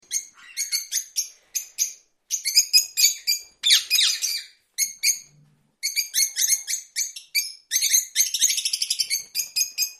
AVES EXÓTICAS
AVES EXOTICAS 2 EFEITO SONORO - Tono movil
aves-exoticas_2_efeito_sonoro_.mp3